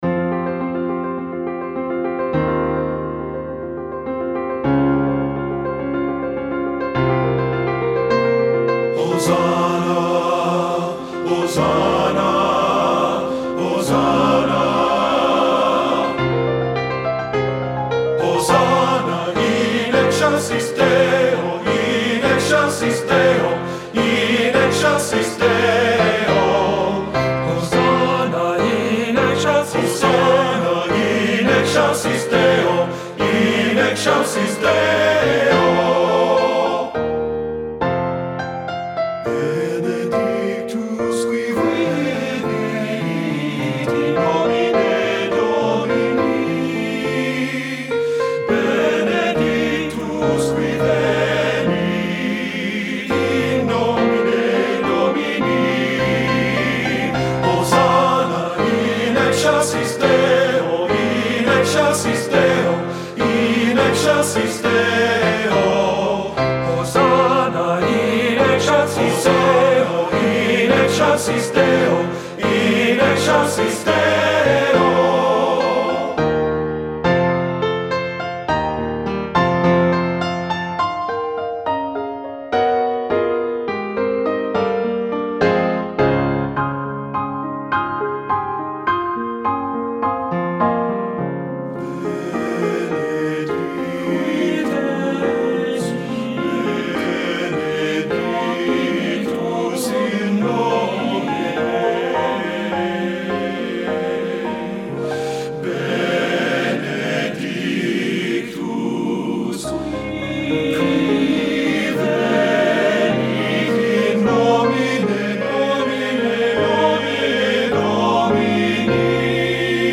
• Tenor 1
• Tenor 2
• Bass 1
• Bass 2
• Piano
Studio Recording
Rhythmically charged
Divisi is limited, and always accessible.
Ensemble: Tenor-Bass Chorus
Accompanied: Accompanied Chorus